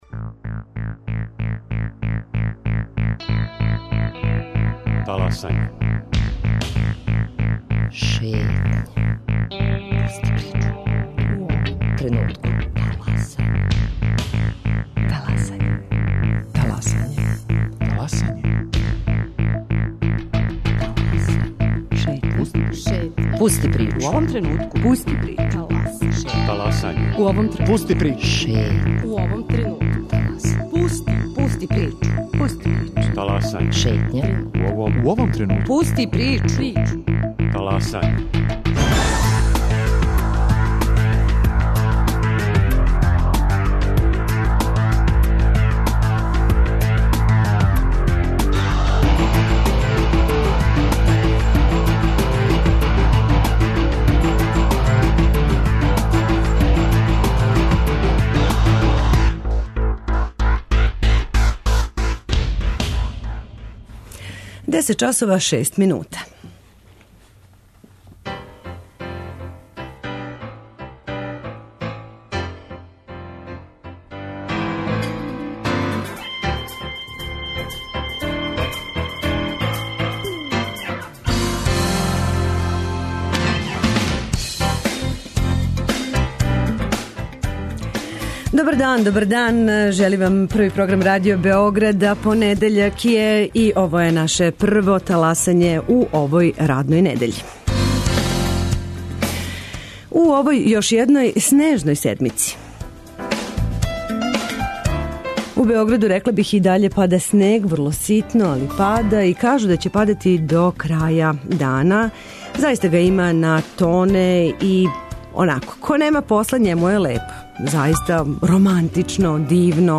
Наша гошћа телефоном из Загреба